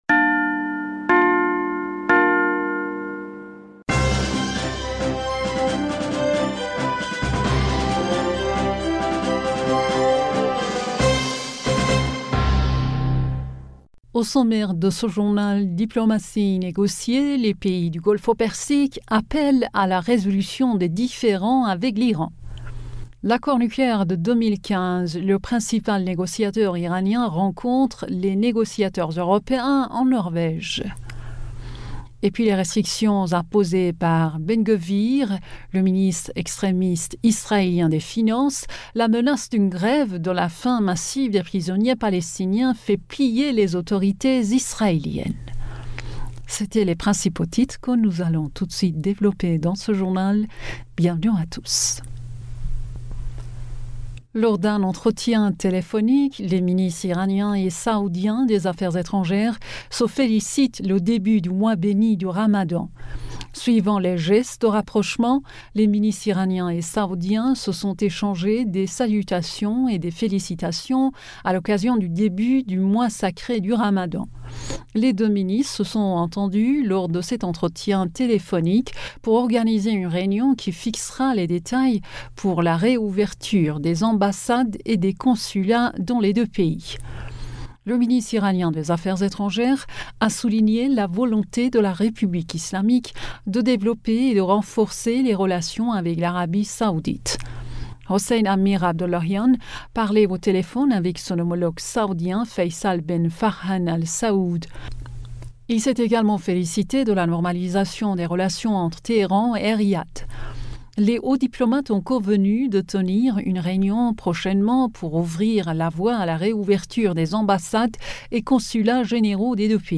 Bulletin d'information du 23 Mars